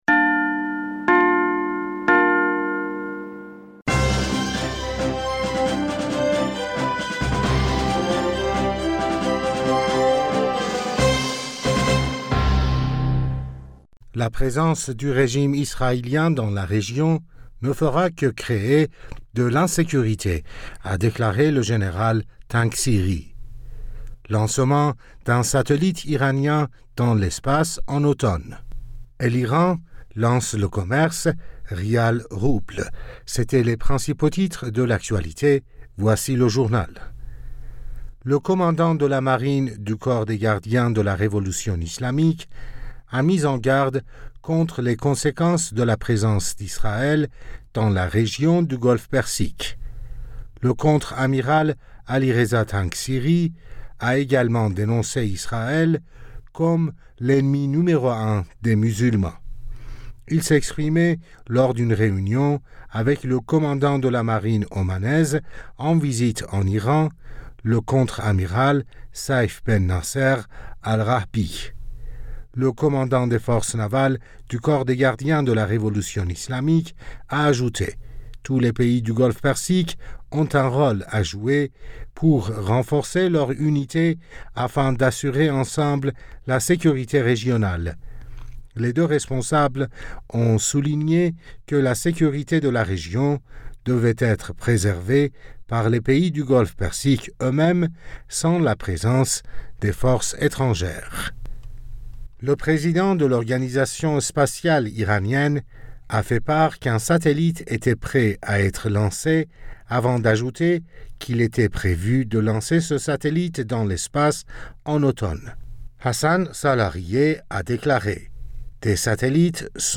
Bulletin d'information Du 23 Julliet